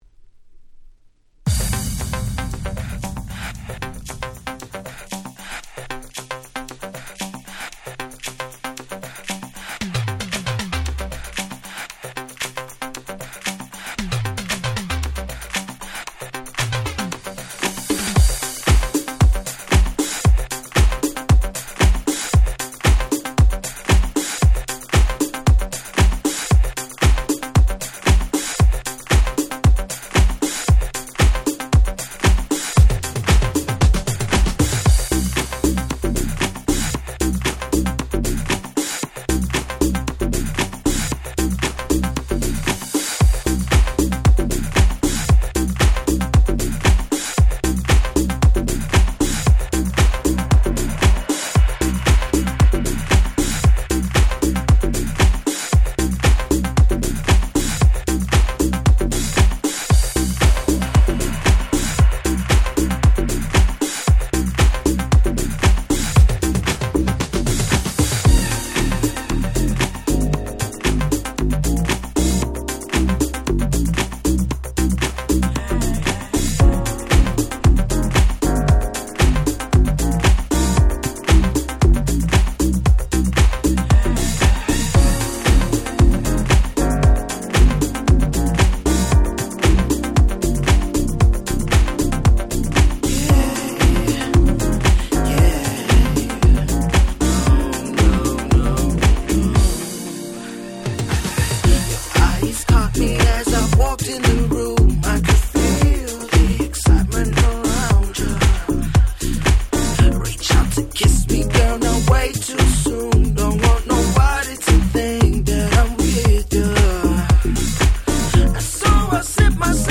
計4トラック収録でどれも最高のDisco Houseに仕上がっております！！